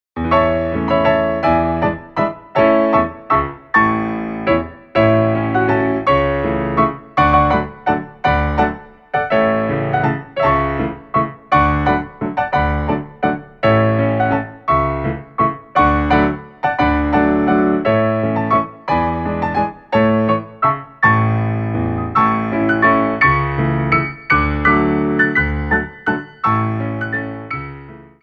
Grand Allegro
3/4 (16x8)